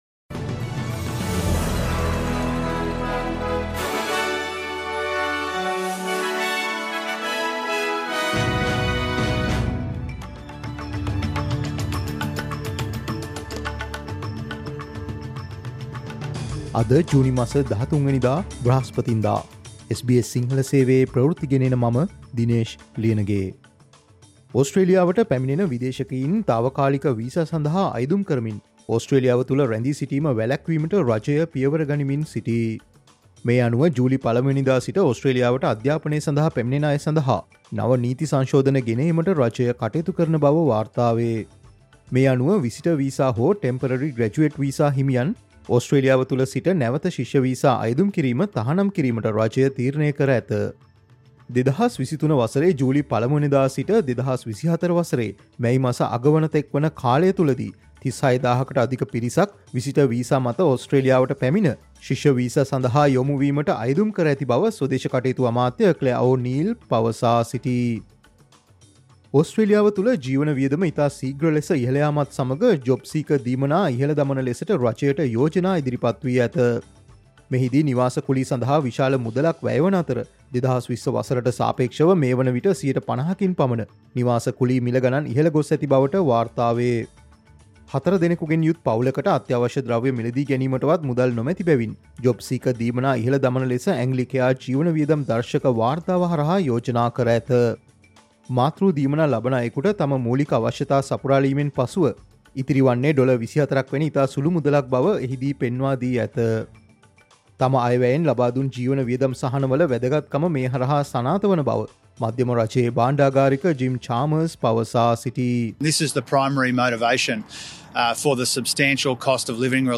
Visitor Visa holders will no longer be able to apply for Student Visas onshore: News Flash June 13